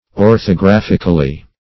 orthographically - definition of orthographically - synonyms, pronunciation, spelling from Free Dictionary
Search Result for " orthographically" : The Collaborative International Dictionary of English v.0.48: Orthographically \Or`tho*graph"ic*al*ly\, adv.